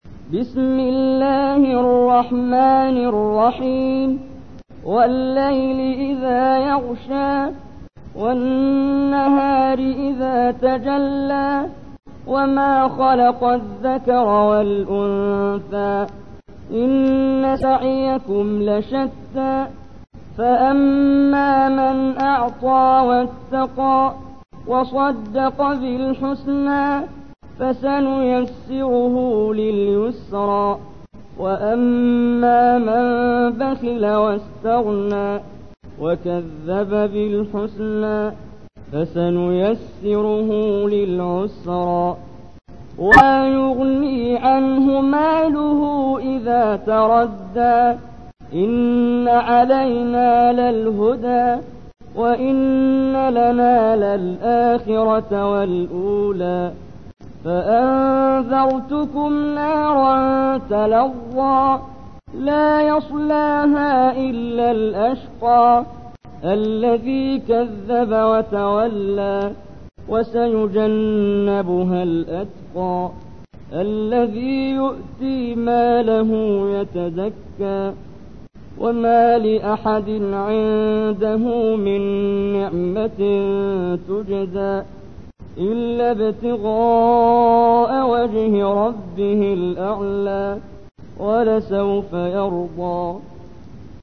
تحميل : 92. سورة الليل / القارئ محمد جبريل / القرآن الكريم / موقع يا حسين